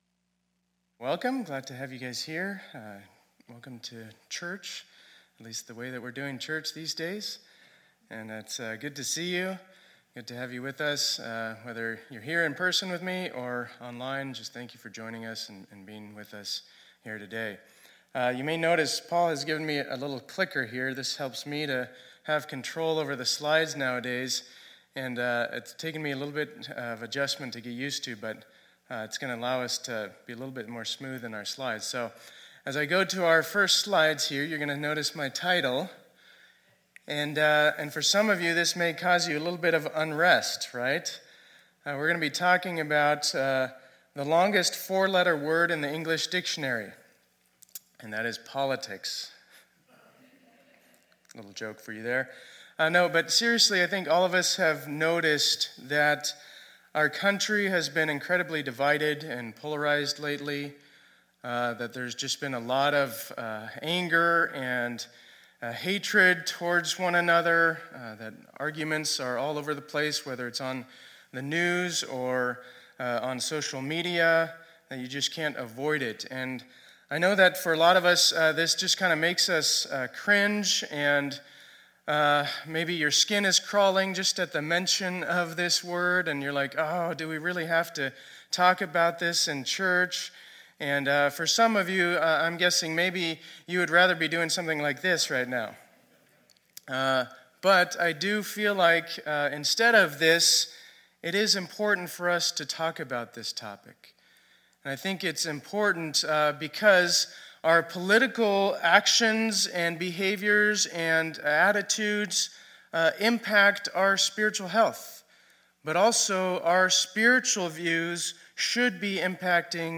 2020-10-11 Sunday Service